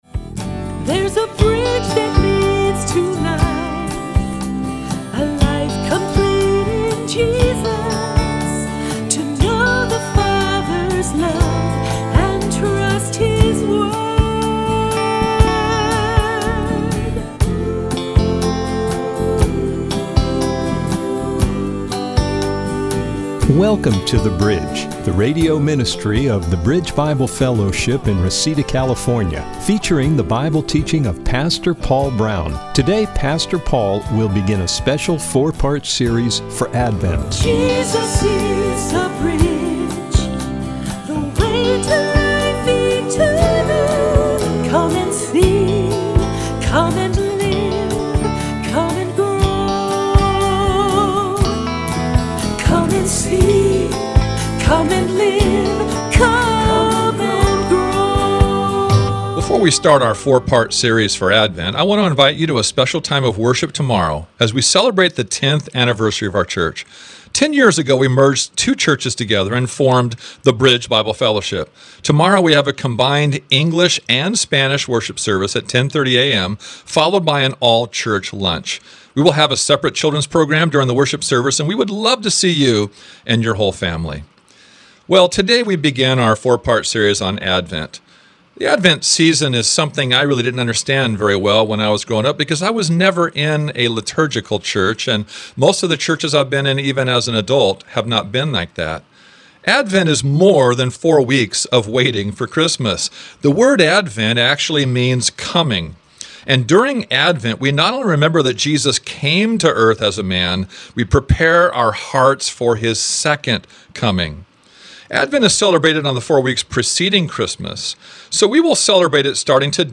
Service Type: Bridge Radio